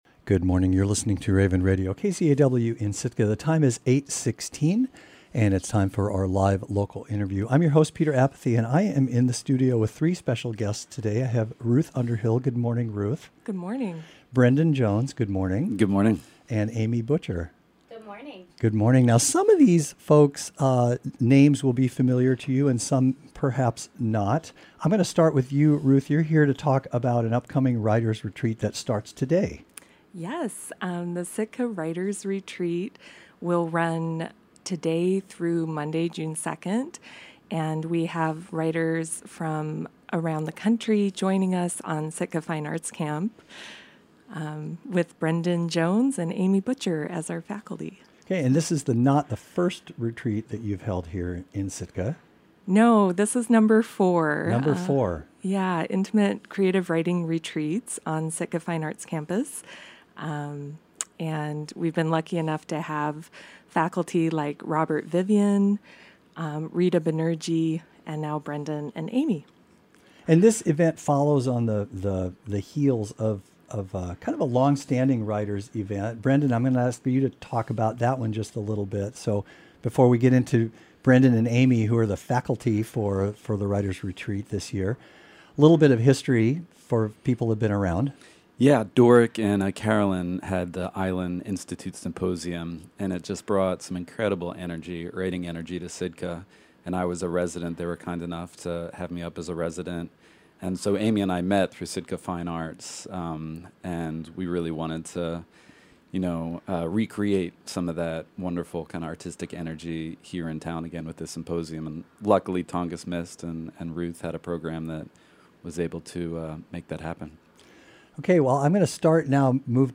Morning Interview